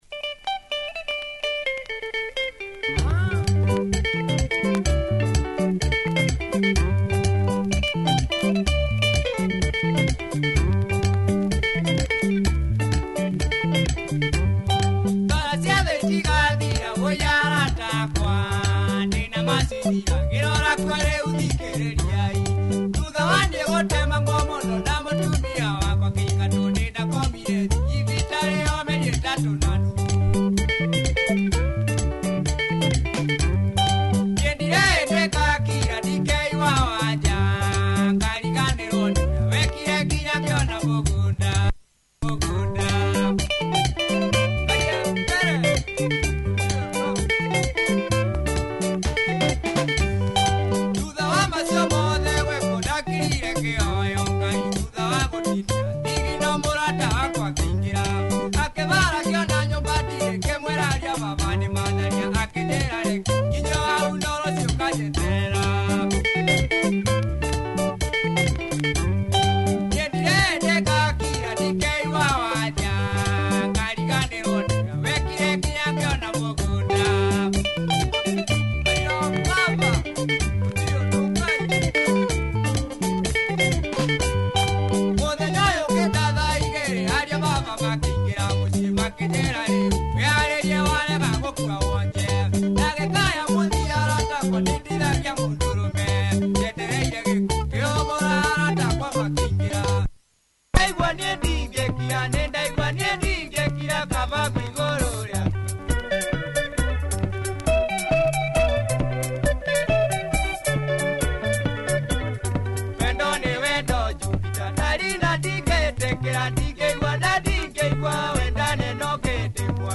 Nice punchy Kikuyu benga